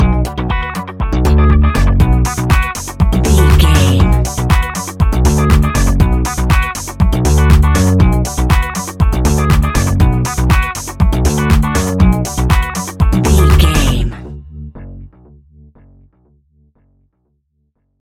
Aeolian/Minor
groovy
futuristic
uplifting
drums
electric guitar
bass guitar
funky house
electronic funk
synths
energetic
upbeat
synth bass
drum machines